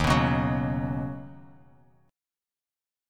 D#m7#5 chord